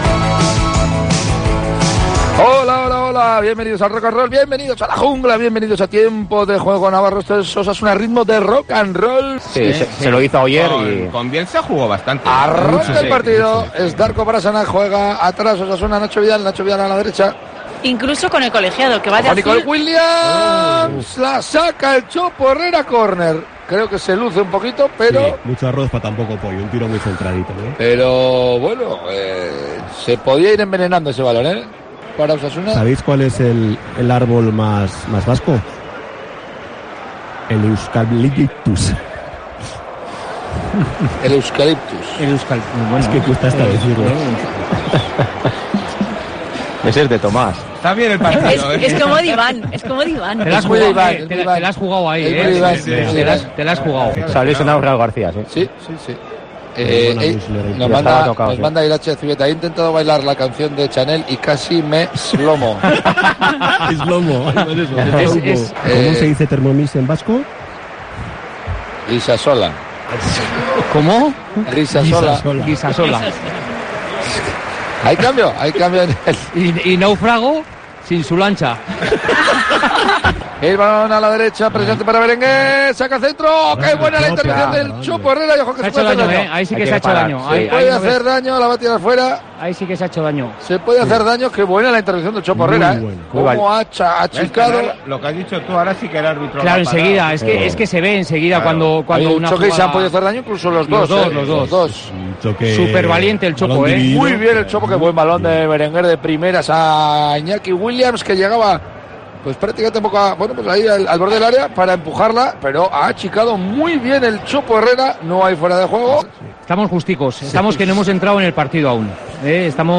Revive los mejores momentos del Athletic-Osasuna (2-0) con la narración